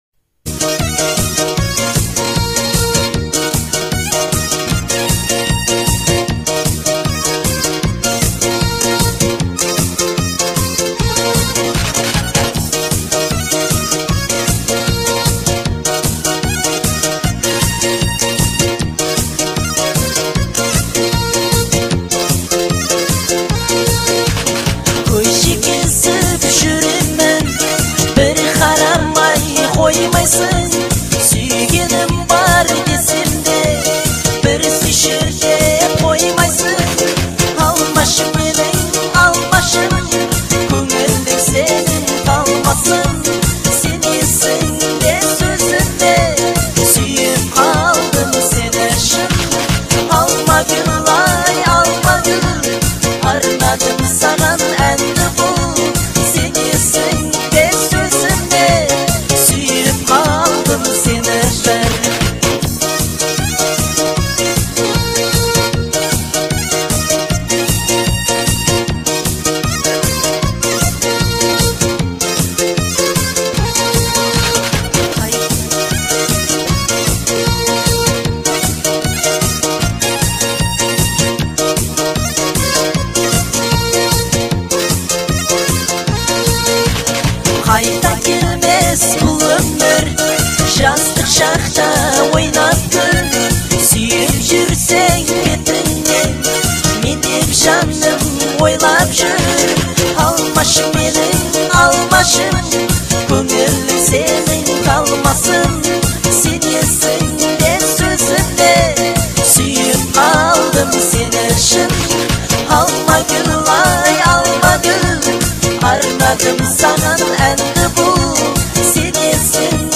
это нежная и трогательная композиция в жанре поп